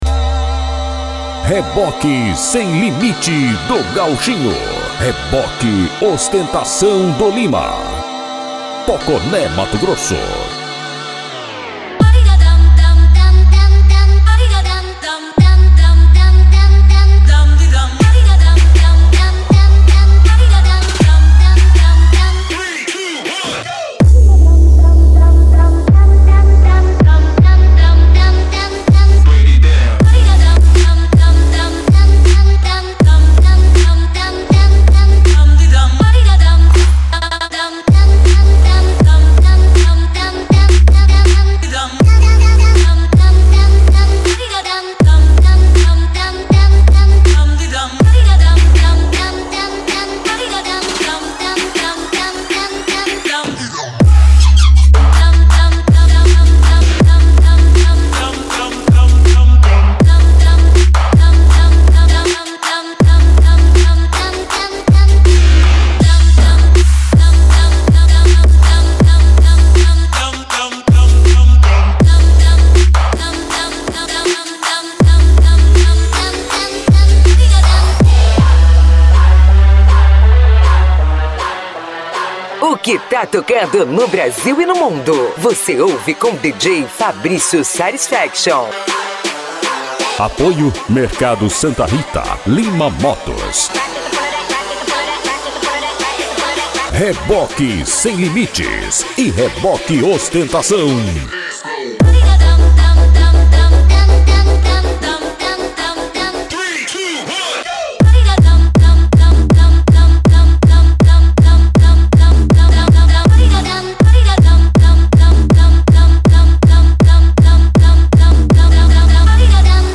Bass
Funk
Mega Funk
Remix